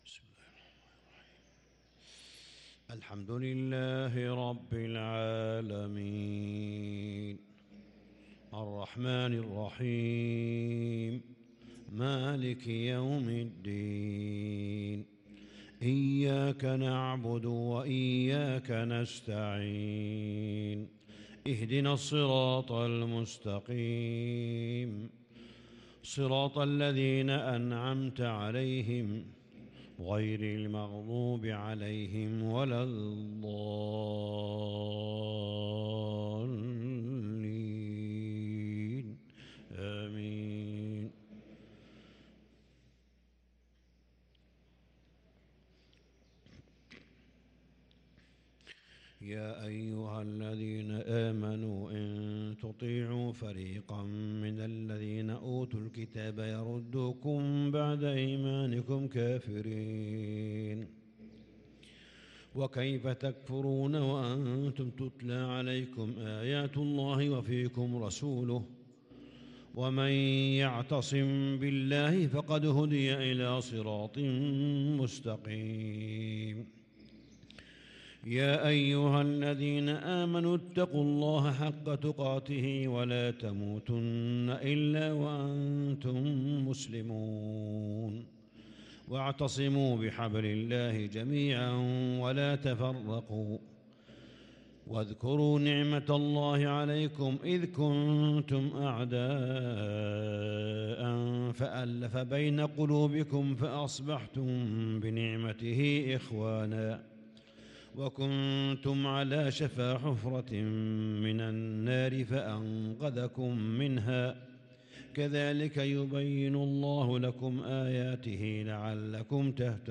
فجر الخميس 6-9-1443هـ من سورة آل عمران | Fajr prayer from Surah Aal-i-Imraan 7/4/2022 > 1443 🕋 > الفروض - تلاوات الحرمين